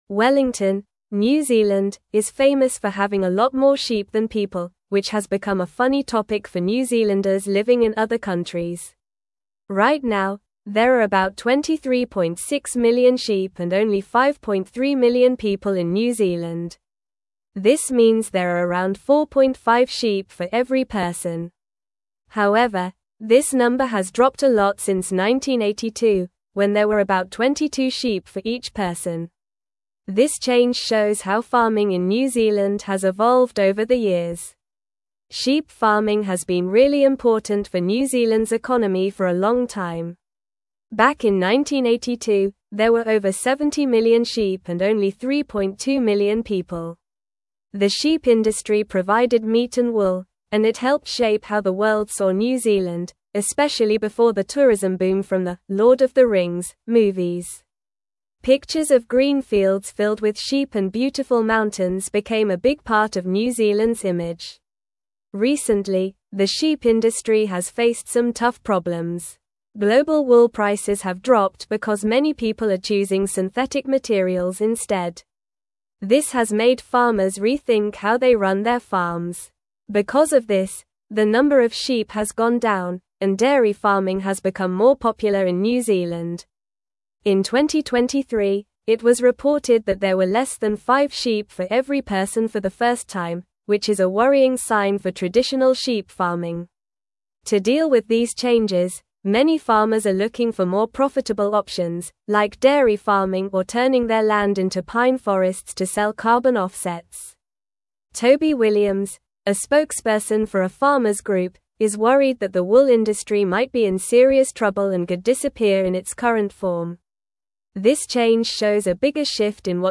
Normal
English-Newsroom-Upper-Intermediate-NORMAL-Reading-Decline-of-New-Zealands-Sheep-Population-and-Industry.mp3